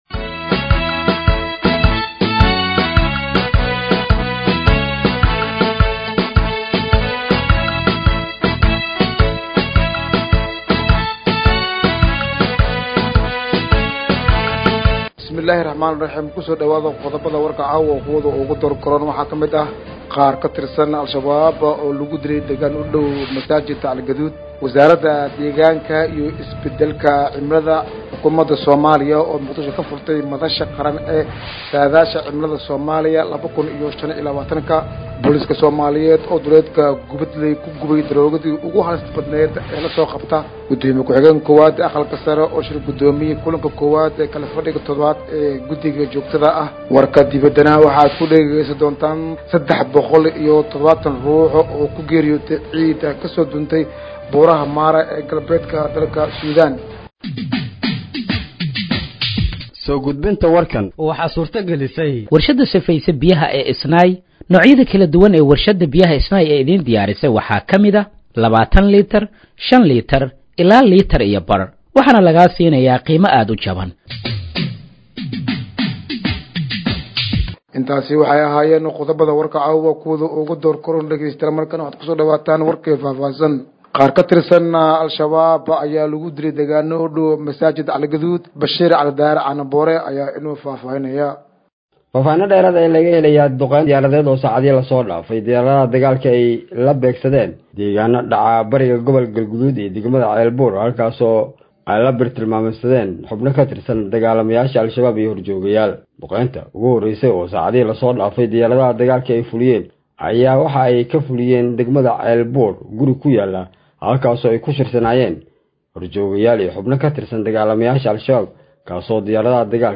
Dhageeyso Warka Habeenimo ee Radiojowhar 02/09/2025